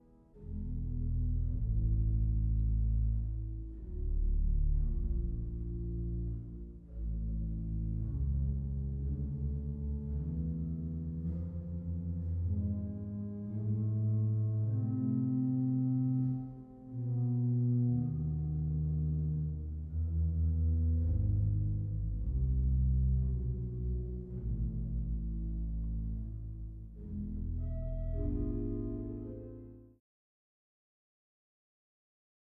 Walcker-Orgel in der St. Annenkirche in Annaberg-Buchholz